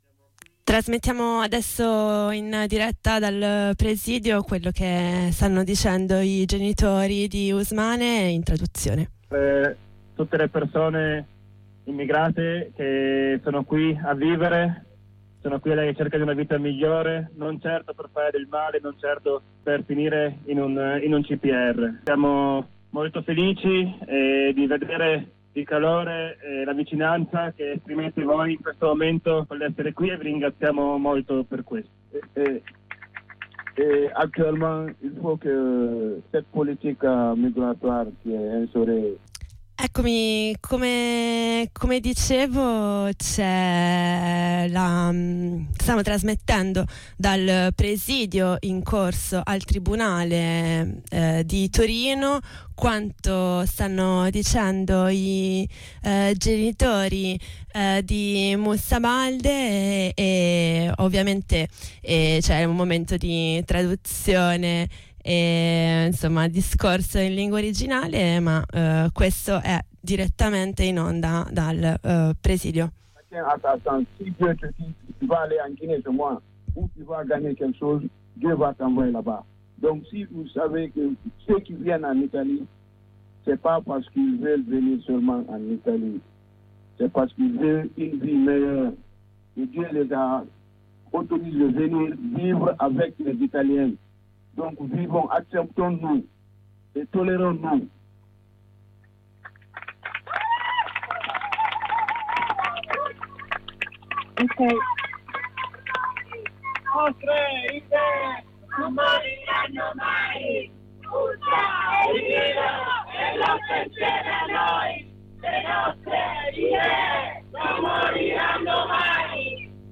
Il presidio è rimasto fuori dal tribunale tutta la mattina, dalle 9 in poi